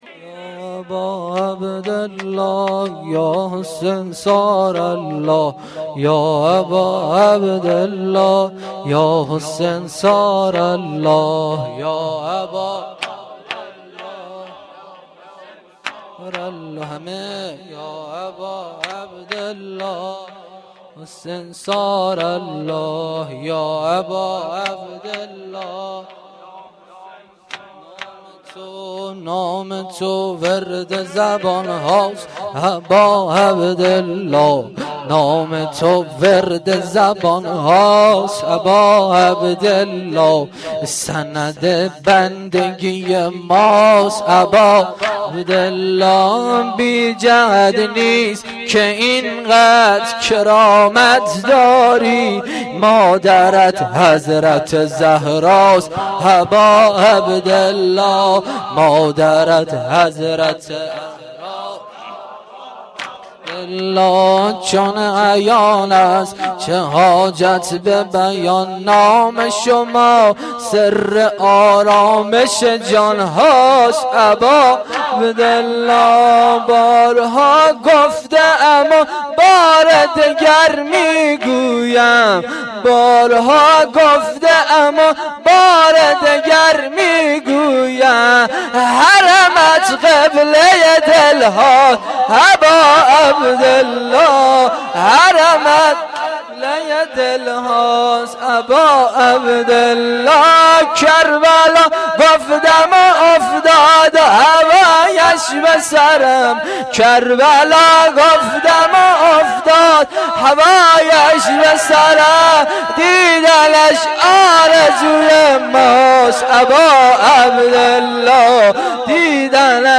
گزارش صوتی جلسه رحلت امام
روضه